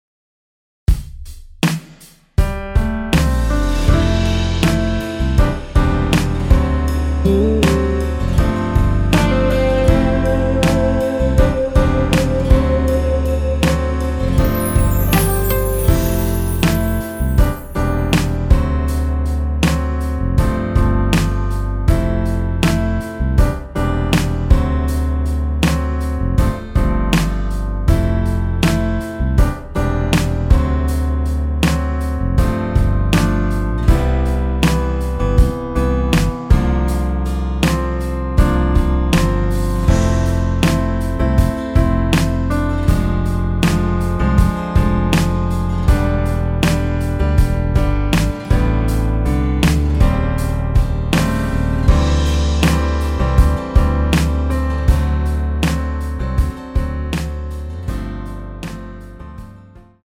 엔딩이 페이드 아웃이라 라이브 하시기 좋게 엔딩을 만들어 놓았습니다.
앞부분30초, 뒷부분30초씩 편집해서 올려 드리고 있습니다.